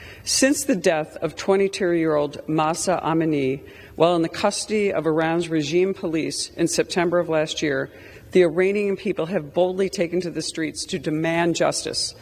The resolution commends the Iranians protesting their governments’ human rights abuses. Congresswoman Tenney spoke on the floor of Congress.